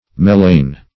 Search Result for " melain" : The Collaborative International Dictionary of English v.0.48: Melain \Mel"ain\, n. [See Melaena .] The dark coloring matter of the liquid of the cuttlefish.